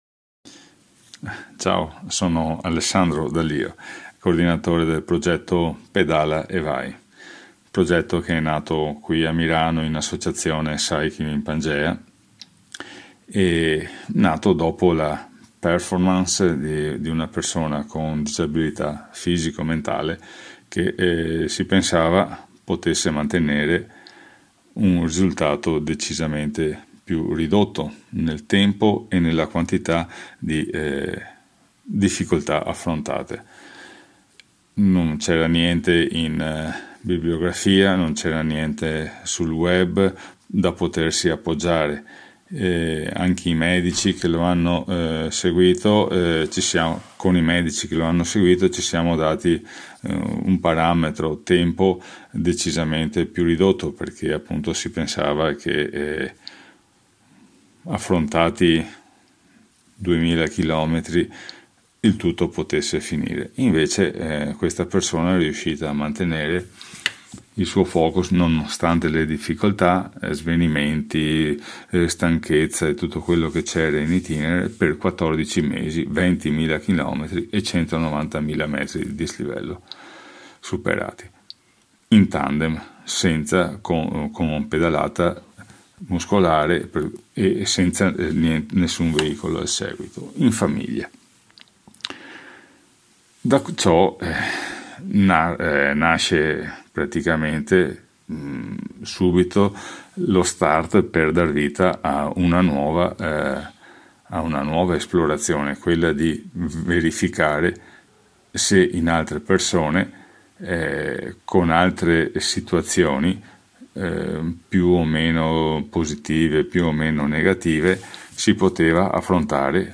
AI MICROFONI DI RADIO PIU’